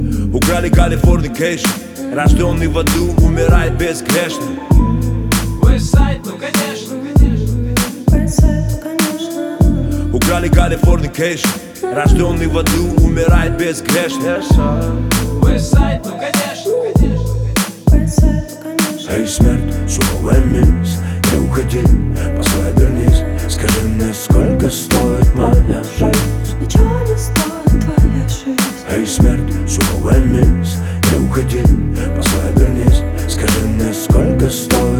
Жанр: Хип-Хоп / Рэп / Русский рэп / Русские